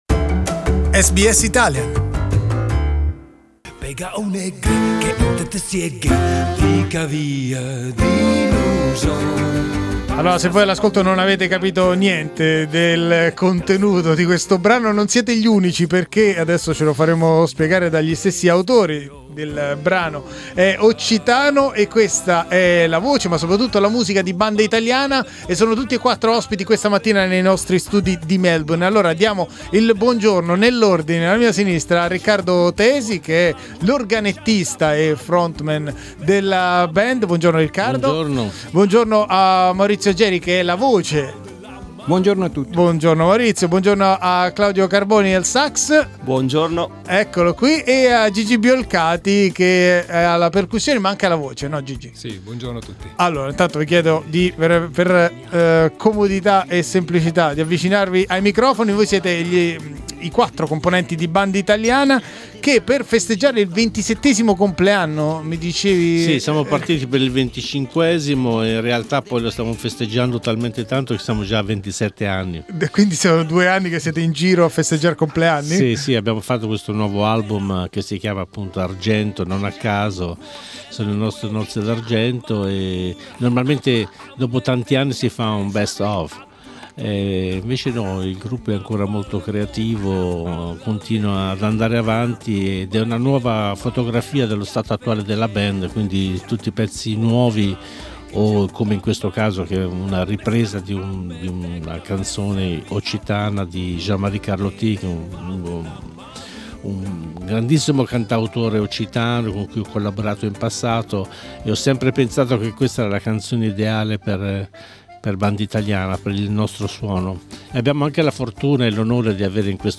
Riccardo Tesi & Banditaliana in our studios Source: SBS Italian